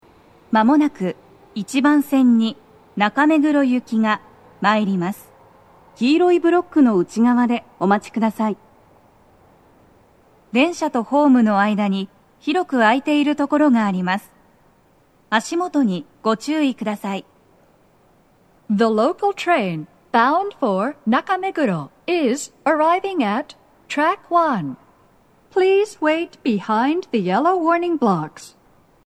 スピーカー種類 BOSE天井型
鳴動は、やや遅めです。
接近放送 【女声